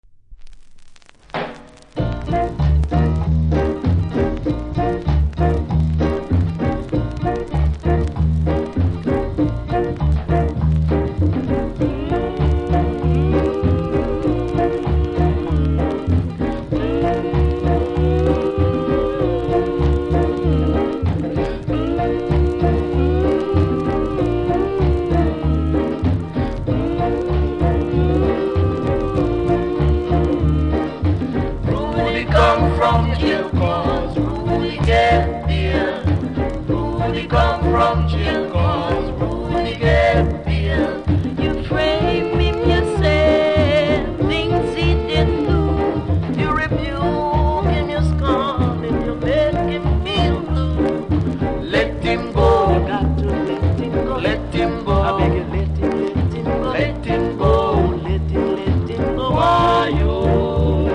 こちらは少しノイズ感じますので試聴で確認下さい。